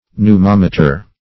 Pneumometer \Pneu*mom"e*ter\, n.